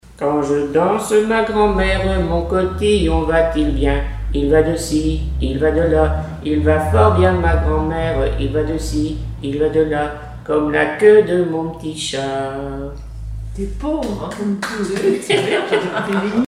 Mémoires et Patrimoines vivants - RaddO est une base de données d'archives iconographiques et sonores.
Couplets à danser
branle : courante, maraîchine
Pièce musicale inédite